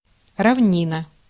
Ääntäminen
IPA: /plɛn/